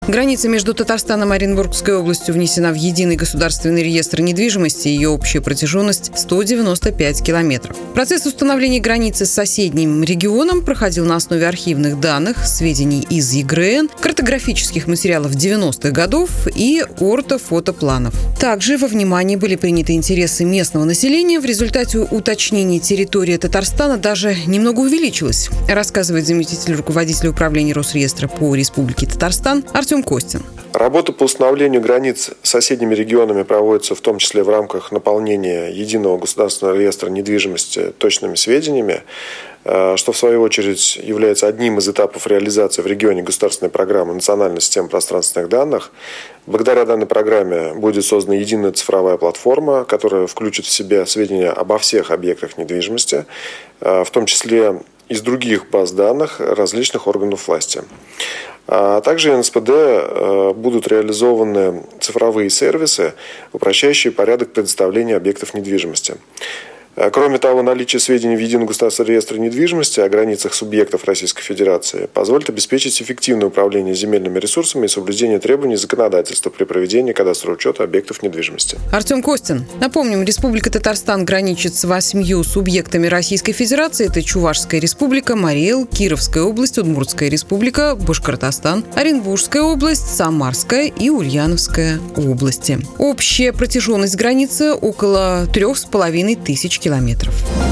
Аудиорепортаж: